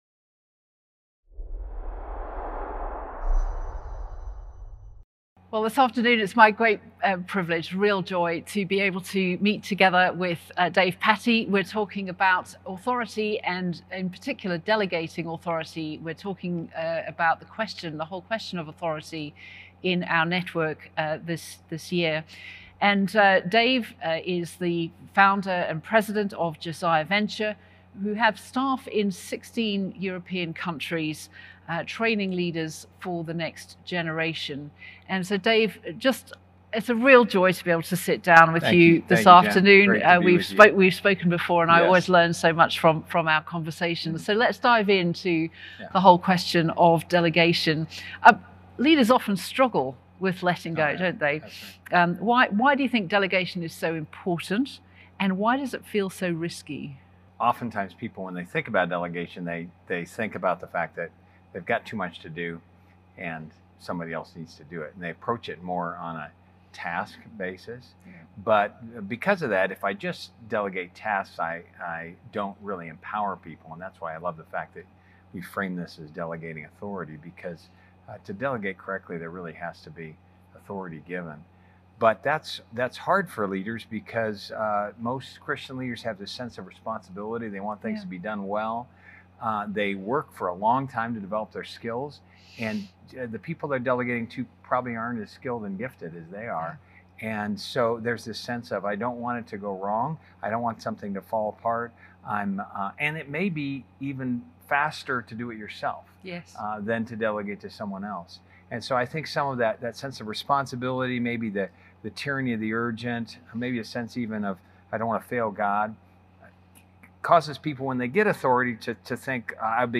Interview: Delegating Like Jesus